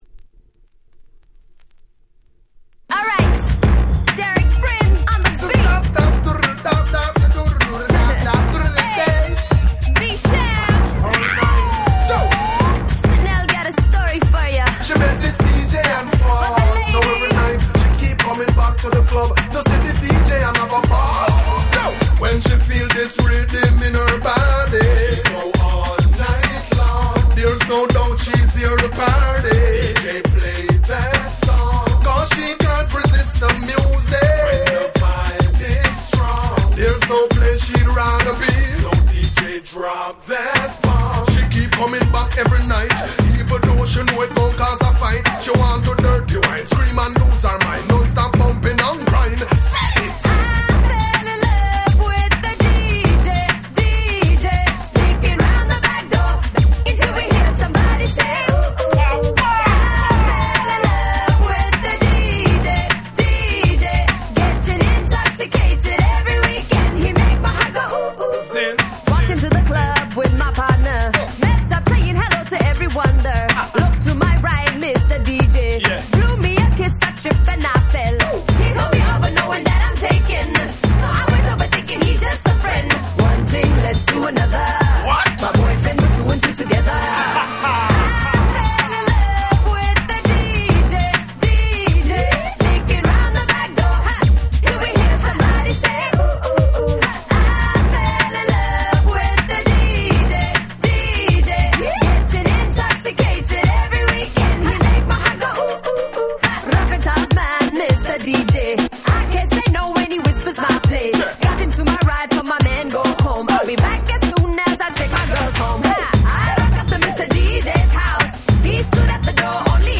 08' Smash Hit R&B !!
Reggae風味で夏にピッタリですね！
レゲエ キャッチー系 00's